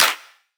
soft-hitclap.wav